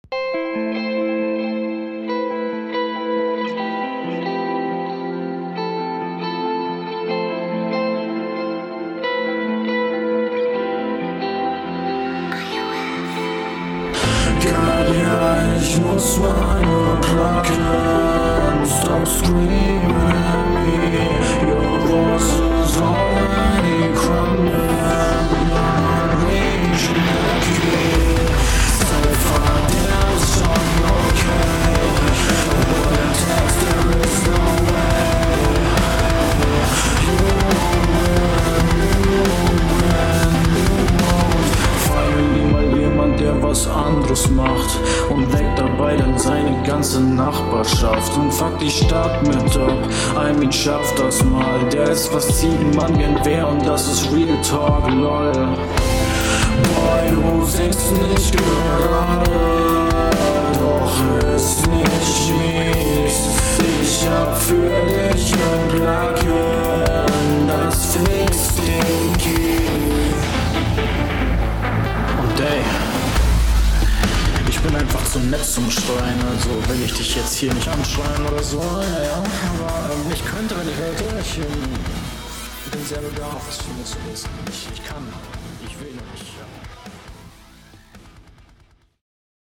Flow: Ich glaube du flowst ganz cool aber du bist leider sehr unverständlich.
Du bist zu leise abgemischt und könntest besser verständlich sein.